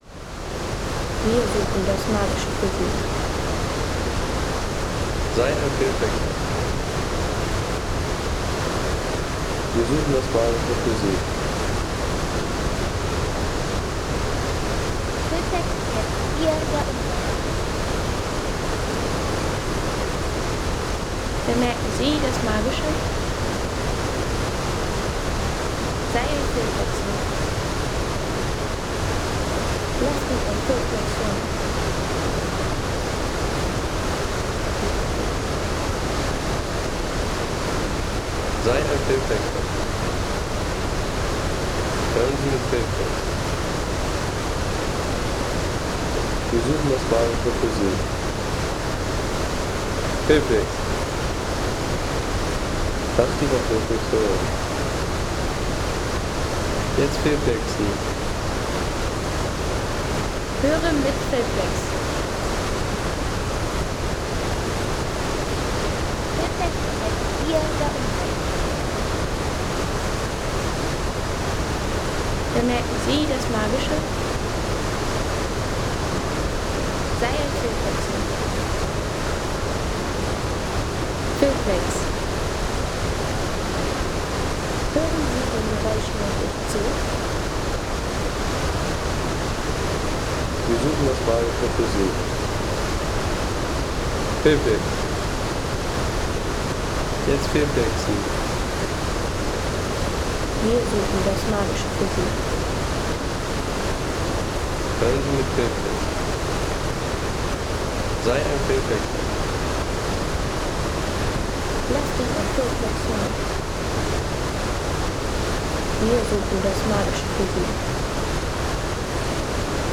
Gedankenwasserfall Home Sounds Landschaft Wasserfälle Gedankenwasserfall Seien Sie der Erste, der dieses Produkt bewertet Artikelnummer: 10 Kategorien: Landschaft - Wasserfälle Gedankenwasserfall Lade Sound.... In dieser Tonaufnahme können Sie Ihren Gedanken freien Lauf lassen 3,50 € Inkl. 19% MwSt.